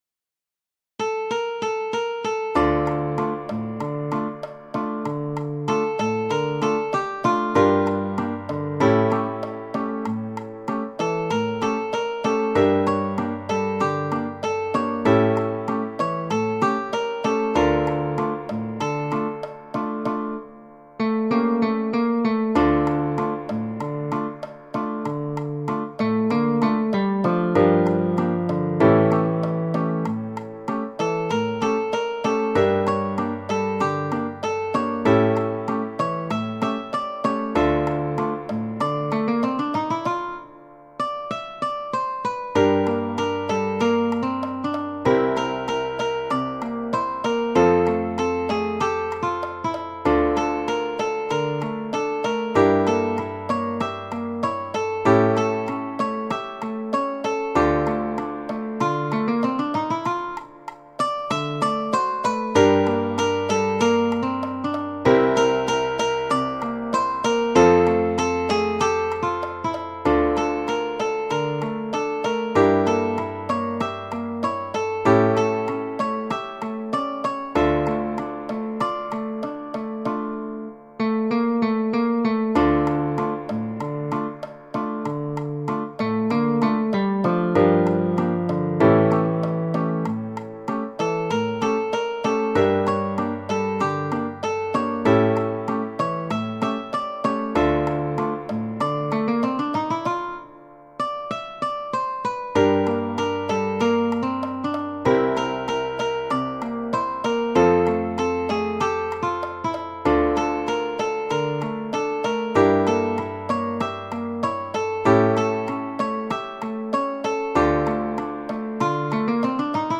romantico bolero